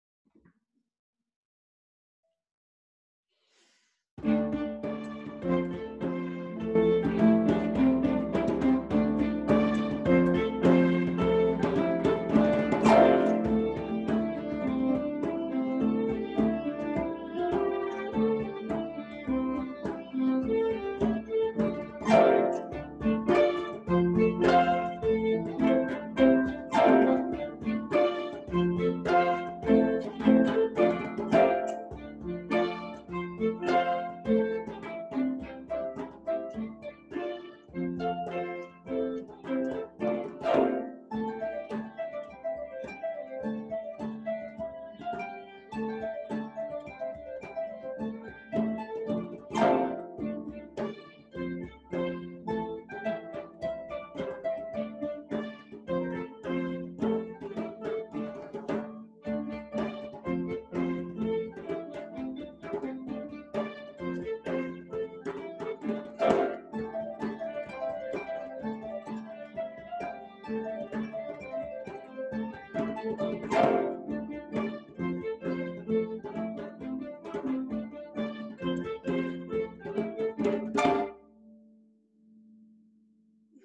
brano tema del Concorso: tradizione celtica
Leprechaun Duo Arpa e Percussioni Rosarthum